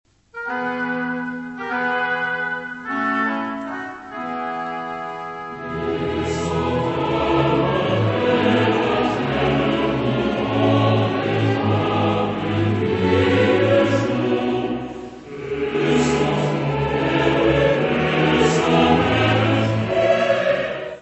: stereo; 12 cm
Music Category/Genre:  Classical Music